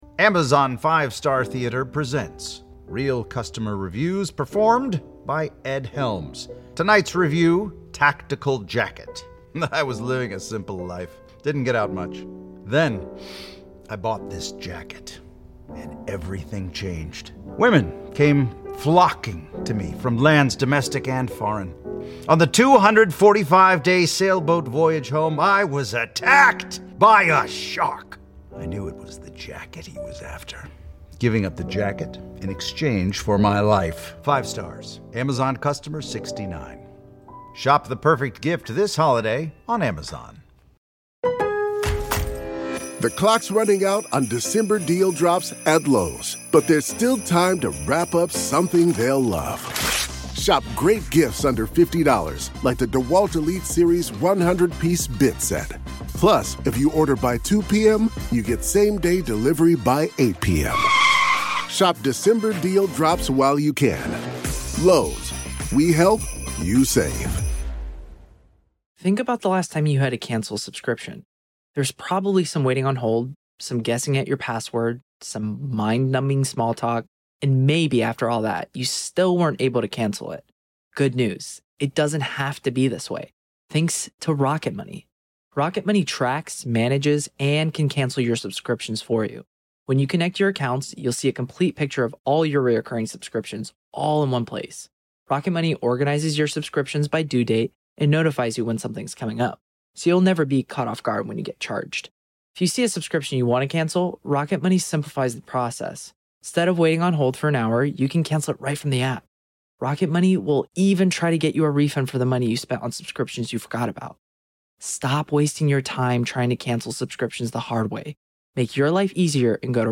Welcome to a new episode of the Hidden Killers Podcast, where we bring you live courtroom coverage of some of the most gripping and heart-wrenching cases.